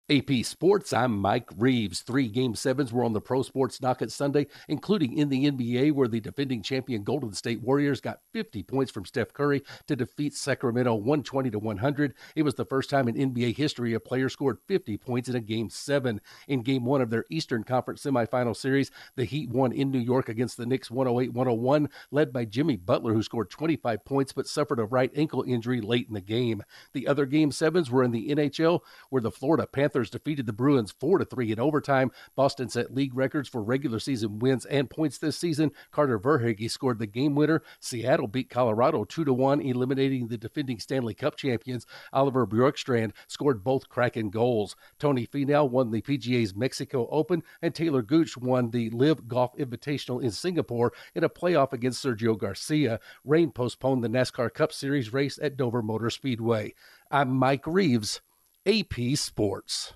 In the NBA the defending champs advance, while in the NHL the defending champs are eliminated, as are the record-setting Bruins. Correspondent